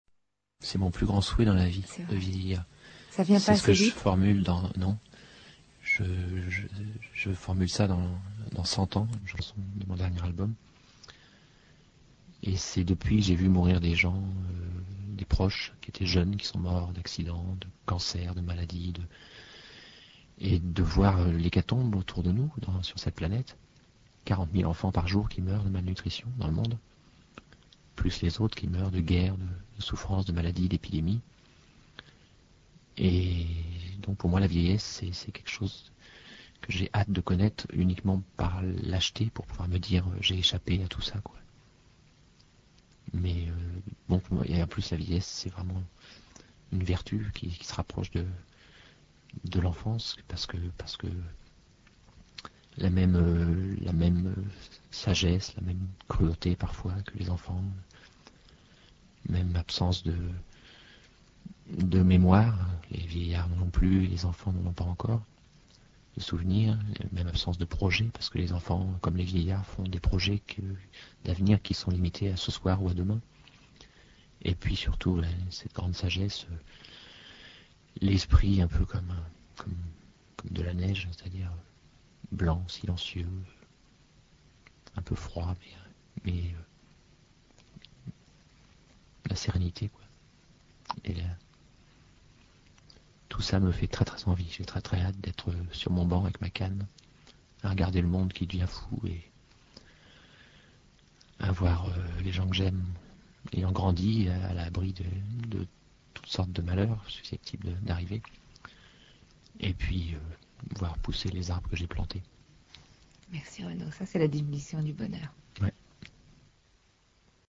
Interview de Renaud à RTL le 9 octobre 1989